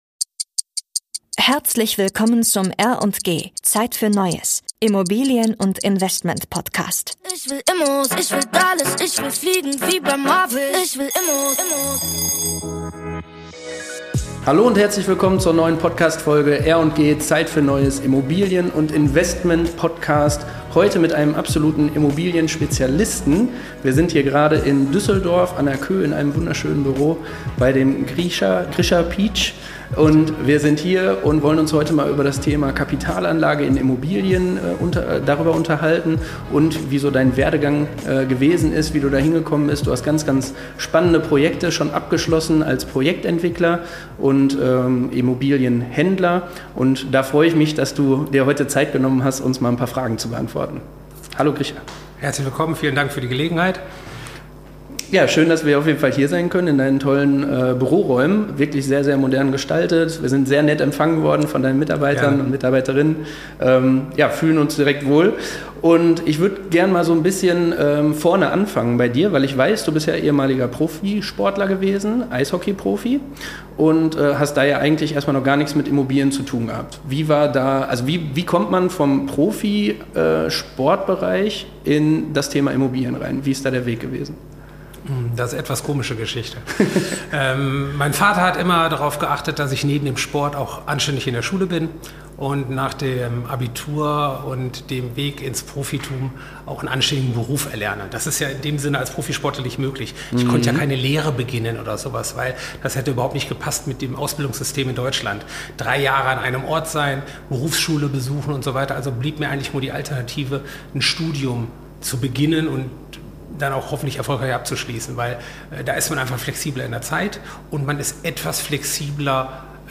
#034 Die Visionäre der Immobilienbranche: Ein Gespräch über Projektentwicklung ~ R&G - Zeit für Neues. Immobilien & Investment Podcast